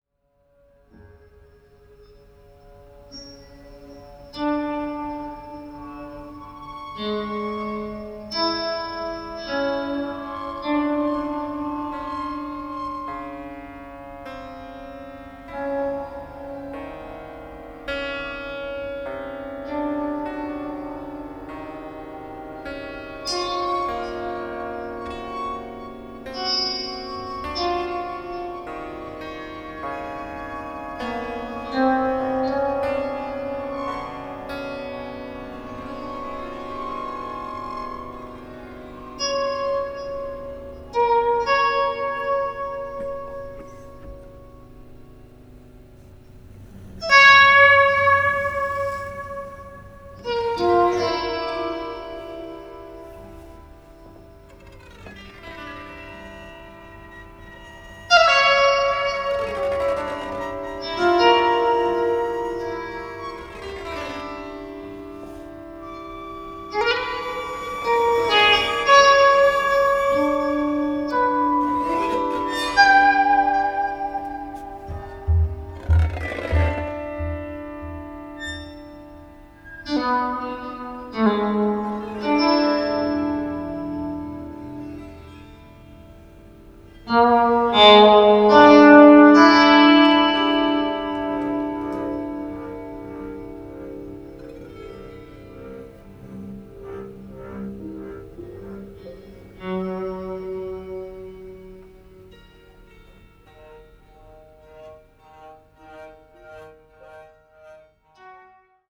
pianoforte
chitarra e synth
Contrabbasso
percussioni